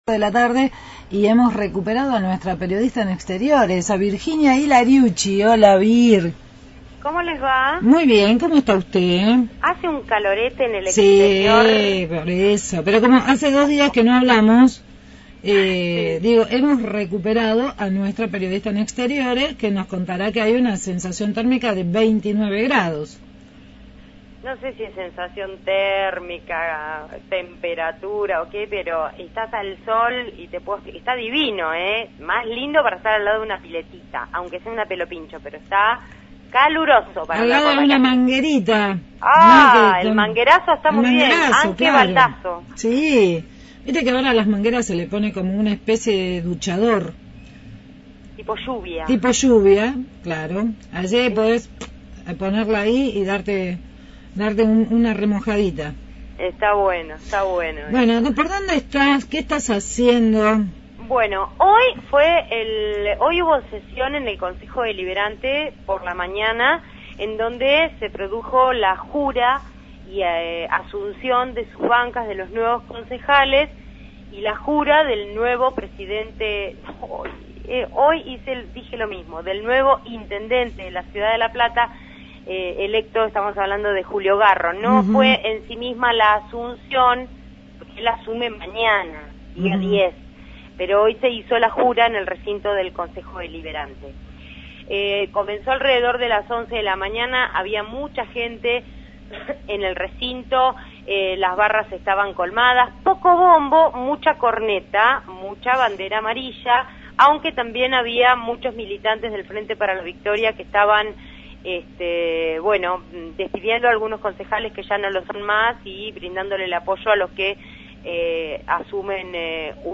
MÓVIL/ Asunción de Julio Garro – Radio Universidad